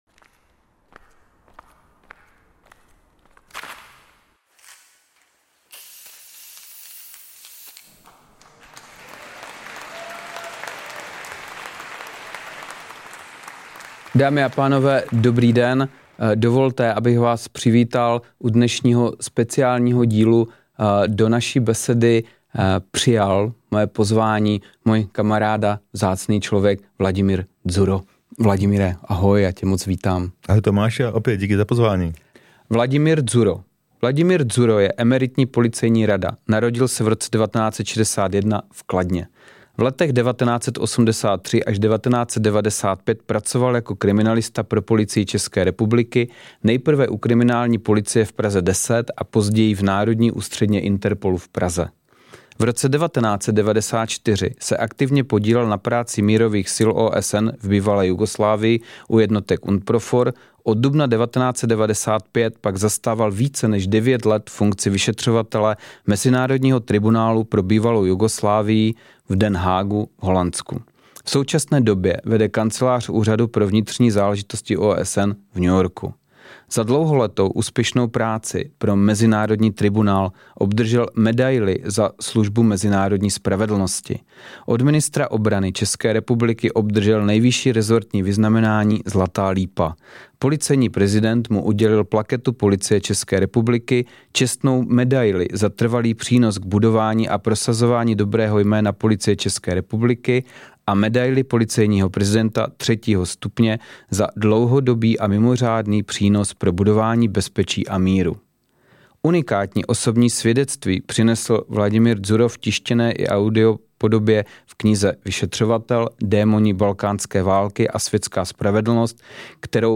Co vede ozbrojené jednotky k tomu, že zabíjí nevinné, včetně dětí? O tom všem si povídáme s bývalým vyšetřovatelem válečných zločinů v bývalé Jugoslávii.